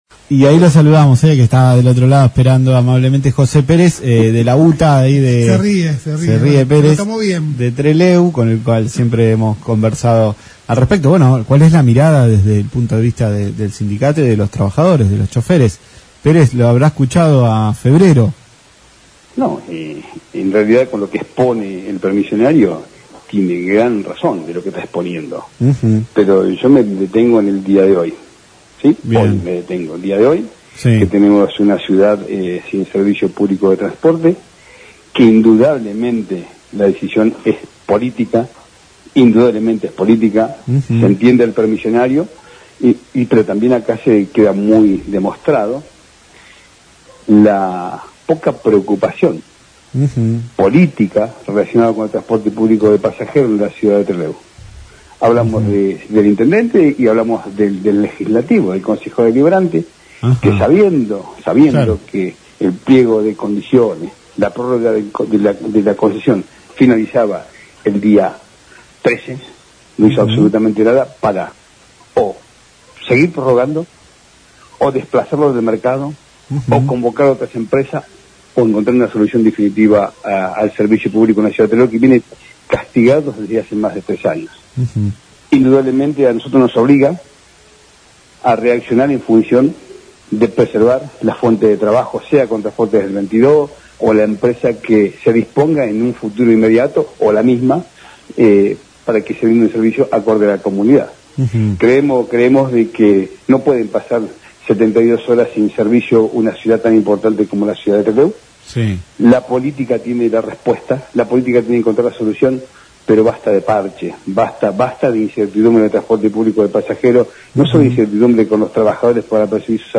En diálogo con LaCienPuntoUno